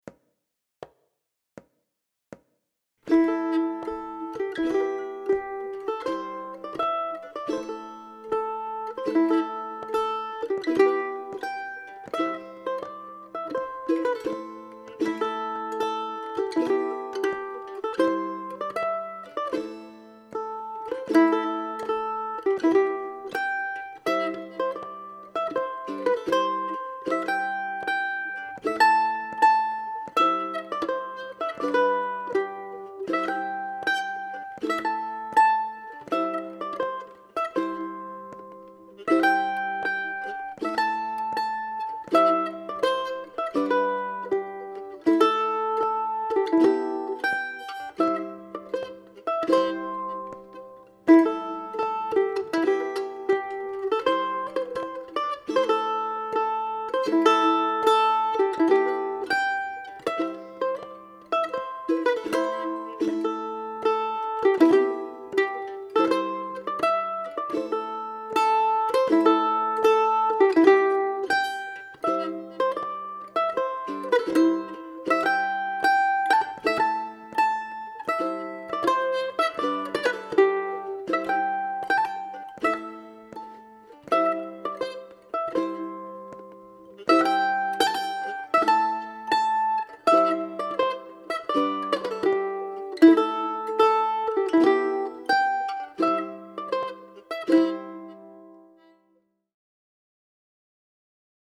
Sandy Buchanan Digital Sheet Music + Online Audio - Mel Bay Publications, Inc. : Mel Bay DIGITAL SHEET MUSIC - MANDOLIN SOLO Celtic/Irish, Mandolin Solo, Standard Notation and Tablature
DIGITAL SHEET MUSIC - MANDOLIN SOLO